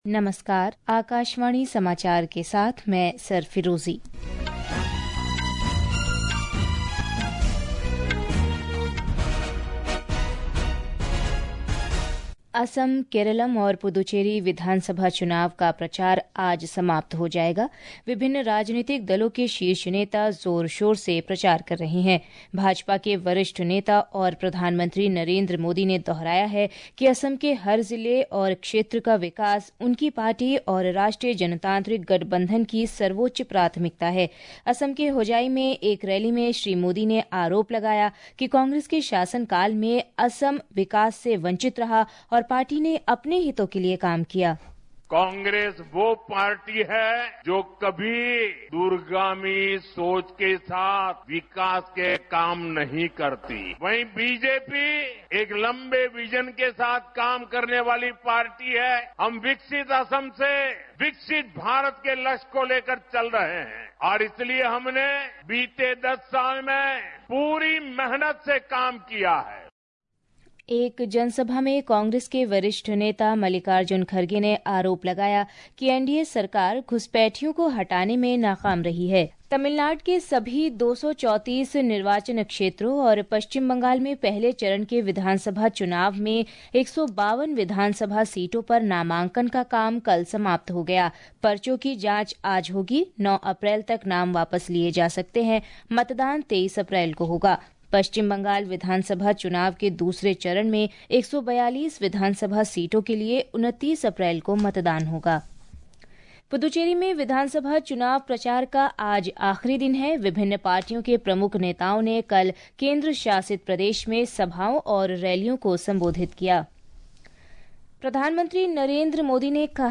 રાષ્ટ્રીય બુલેટિન
Hourly News